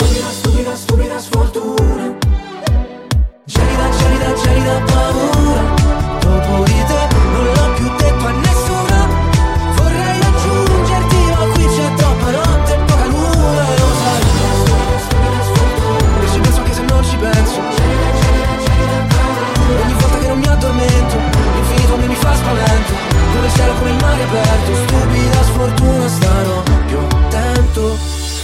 Genere: italiana,sanremo2026,pop.ballads,rap,hit